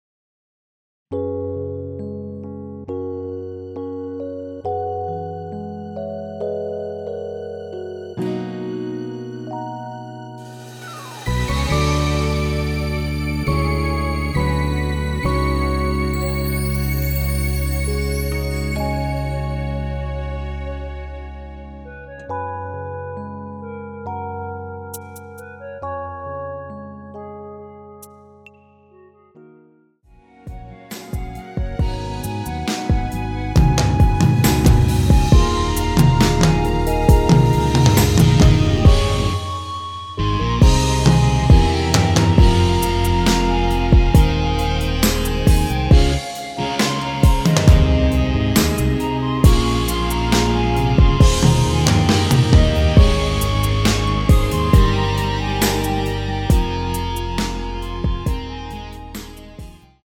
원키에서(-2)내린 멜로디 포함된 MR입니다.(미리듣기 확인)
F#
앞부분30초, 뒷부분30초씩 편집해서 올려 드리고 있습니다.
중간에 음이 끈어지고 다시 나오는 이유는